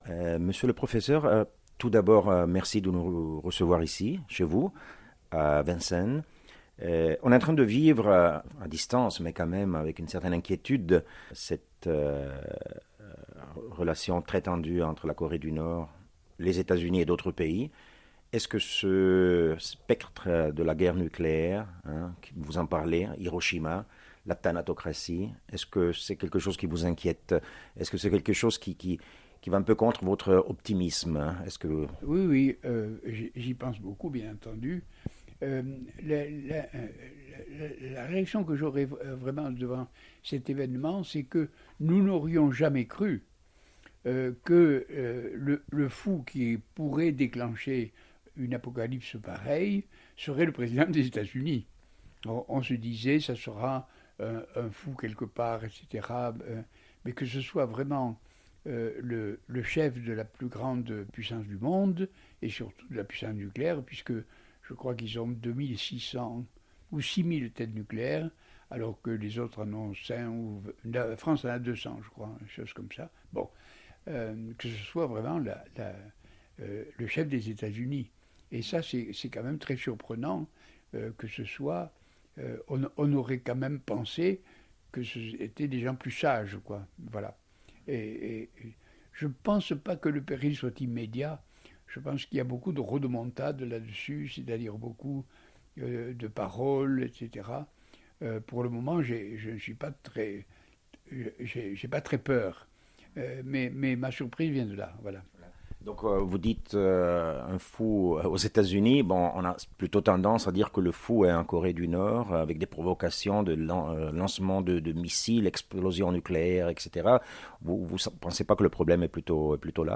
Intervista a Michel Serres in lingua originale